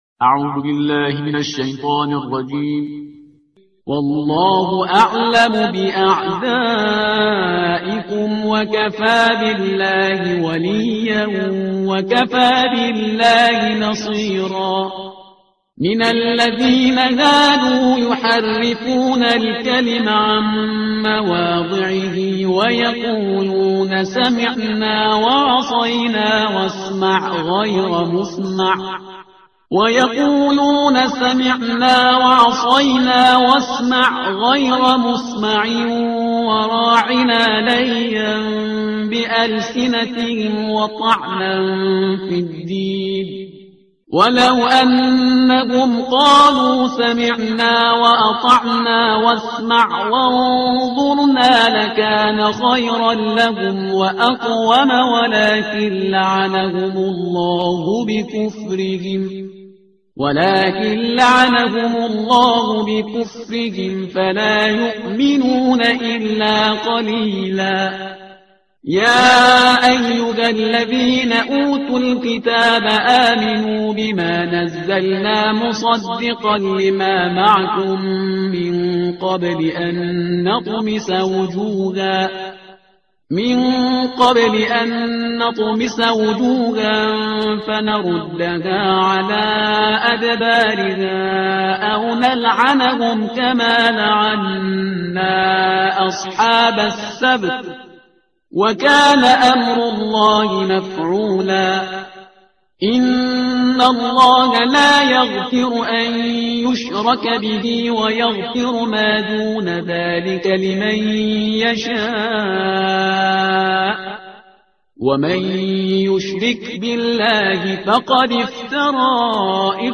ترتیل صفحه 86 قرآن با صدای شهریار پرهیزگار